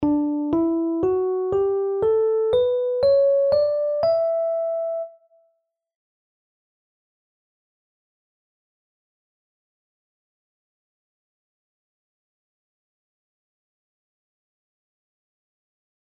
Grote secunde